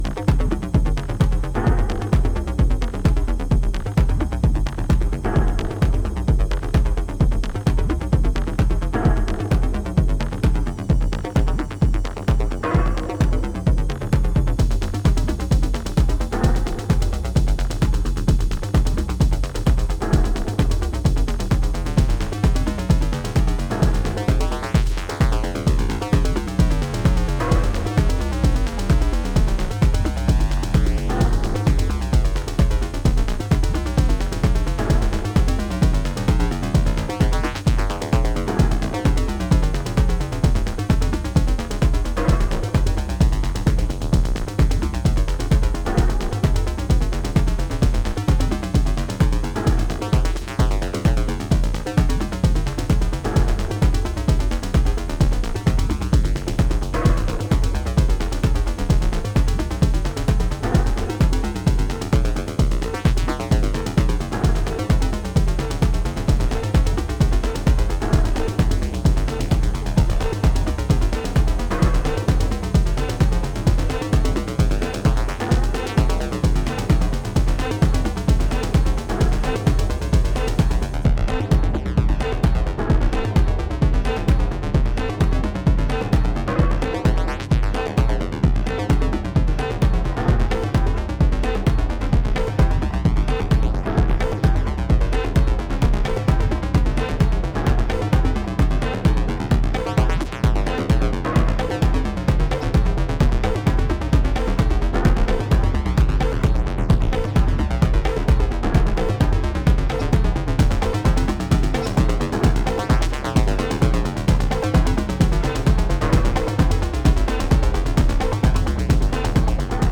EBM/EU産初期テクノにも通じるストーンドな